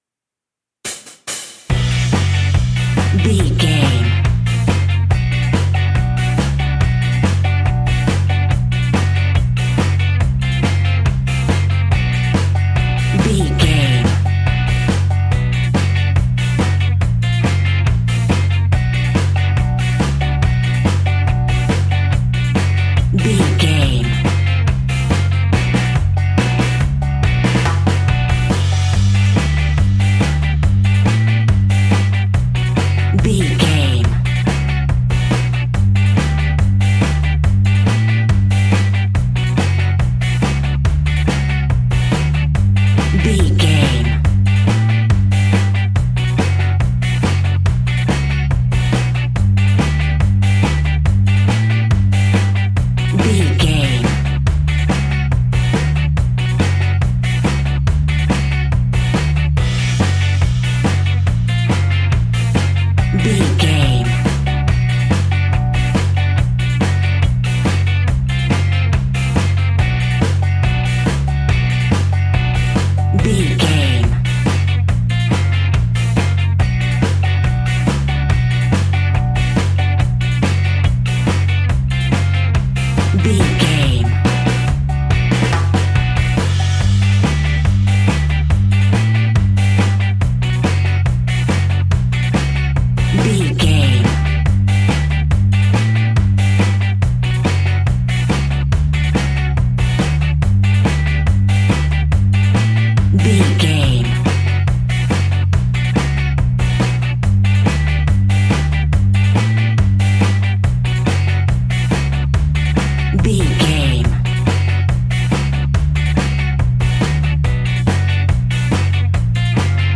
Aeolian/Minor
dub
laid back
chilled
off beat
drums
skank guitar
hammond organ
percussion
horns